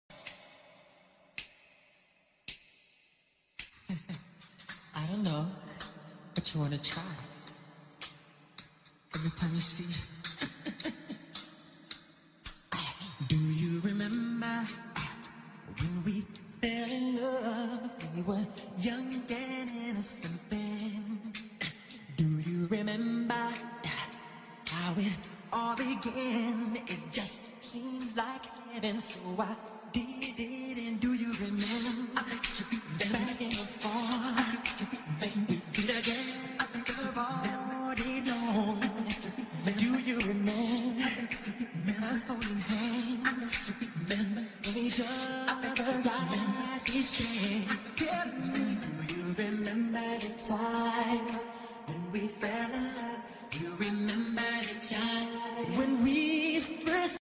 sonido 8d reverberacion cinematic#16d #8d